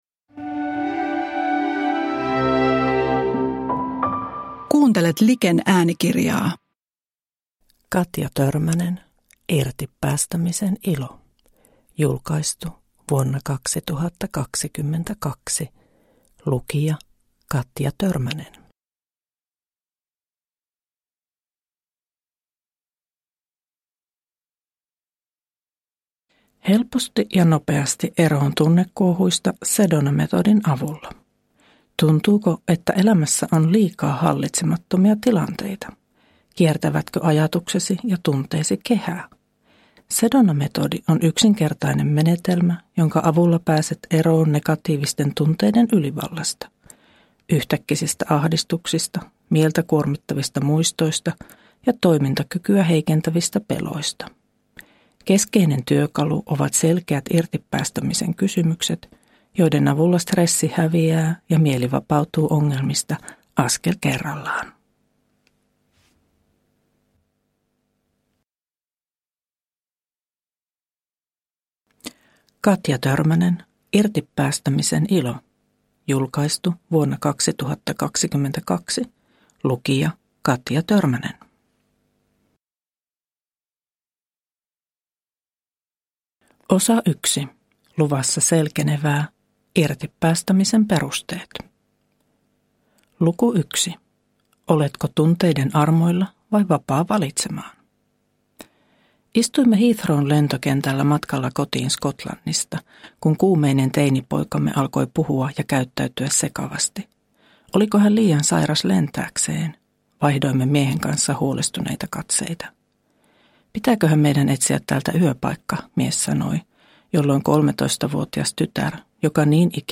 Irti päästämisen ilo – Ljudbok – Laddas ner